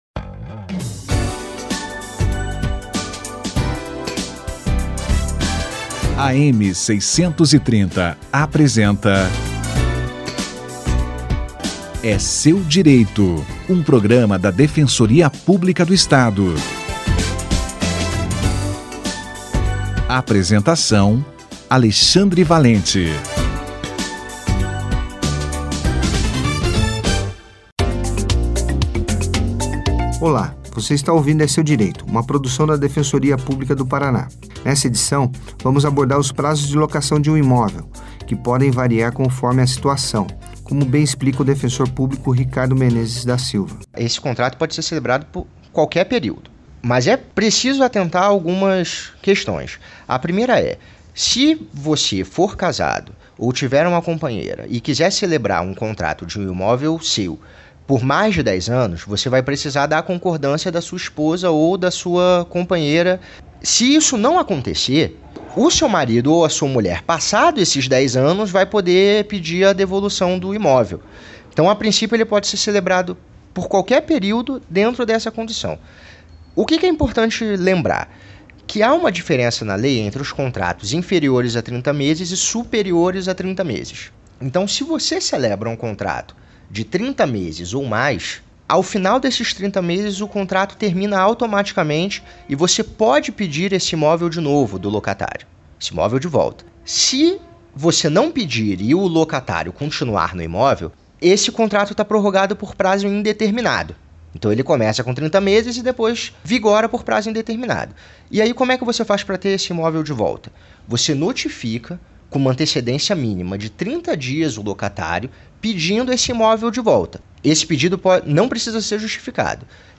Lei do Inquilinato/prazos - Entrevista